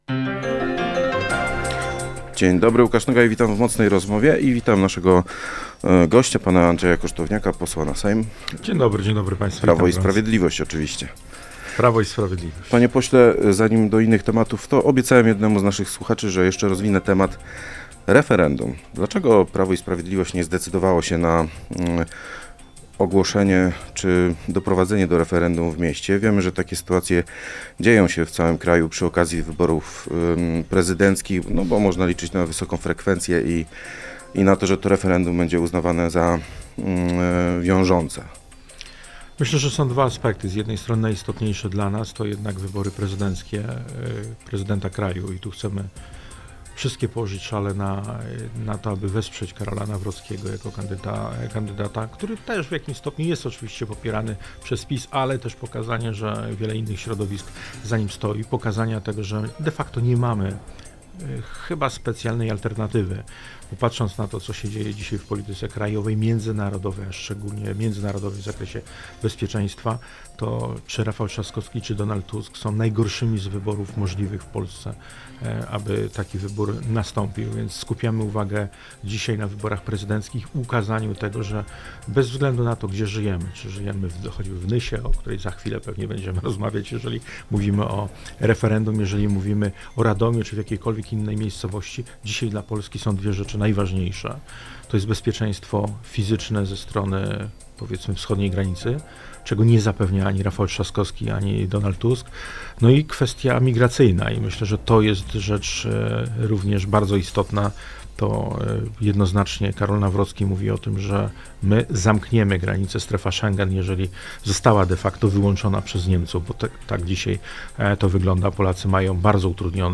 Poseł Prawa i Sprawiedliwości Andrzej Kosztowniak